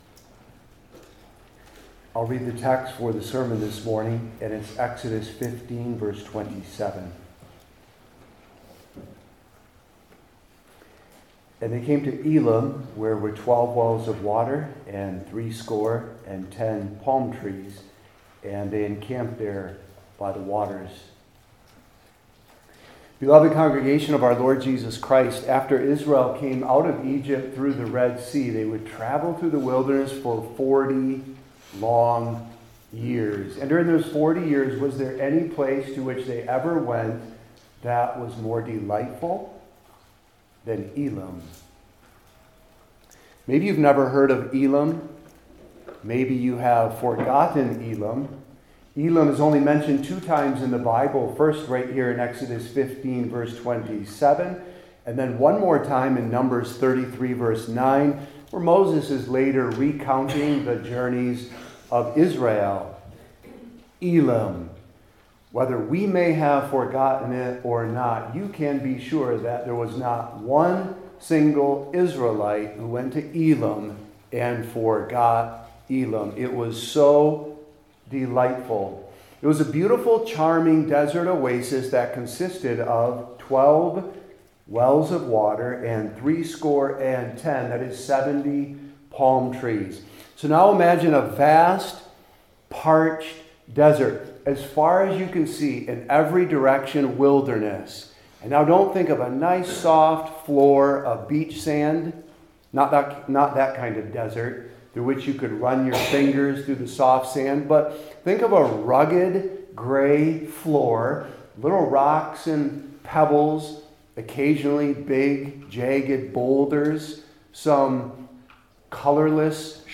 Old Testament Individual Sermons I. Then II.